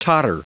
Prononciation du mot totter en anglais (fichier audio)
Prononciation du mot : totter